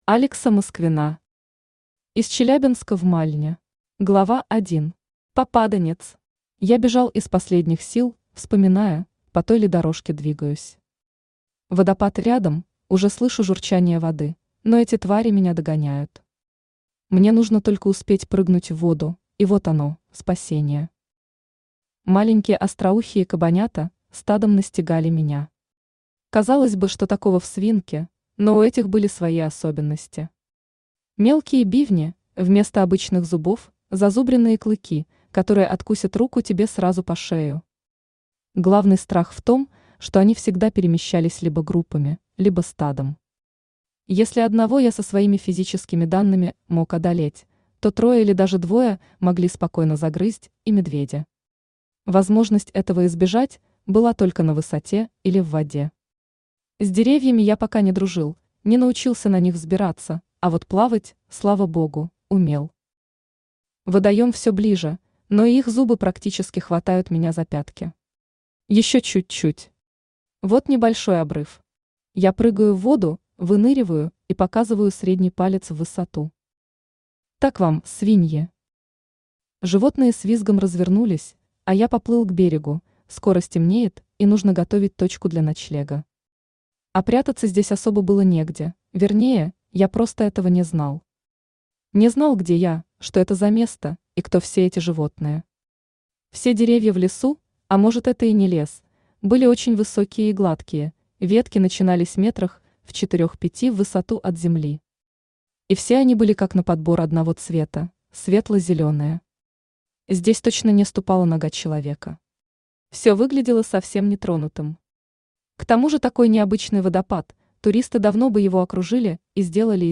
Aудиокнига Из Челябинска в Мальне Автор Алекса Москвина Читает аудиокнигу Авточтец ЛитРес.